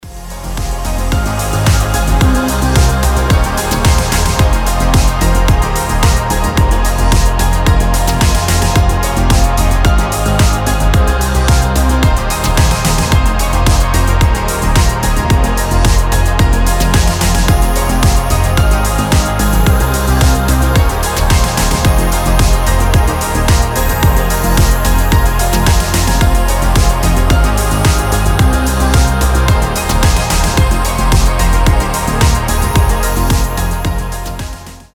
• Качество: 320, Stereo
мелодичные
Electronic
без слов